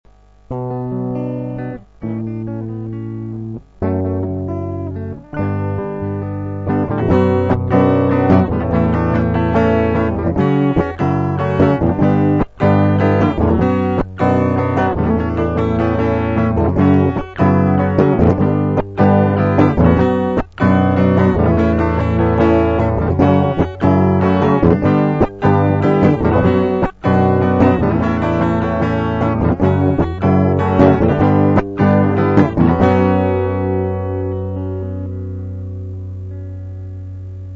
Проигрыш (Hm - A - F#m - G):